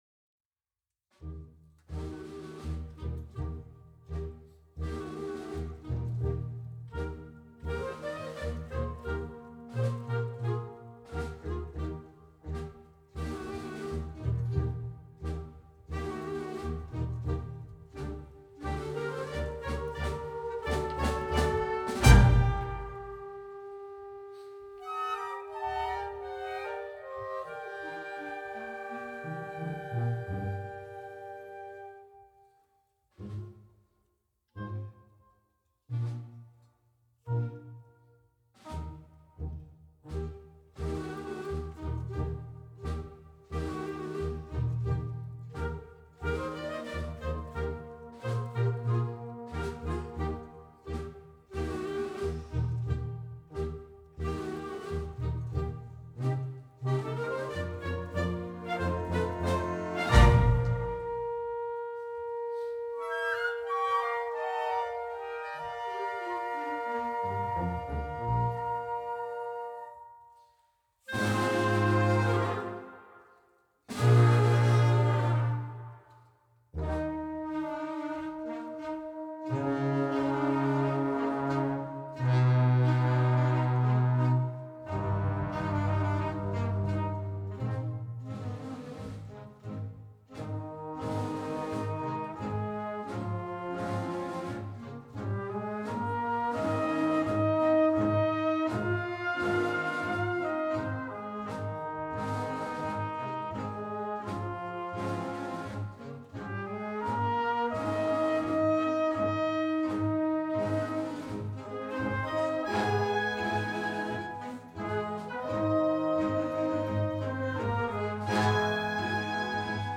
Ecco alcuni dei brani eseguiti più di frequente negli ultimi anni e alcune registrazioni dal vivo.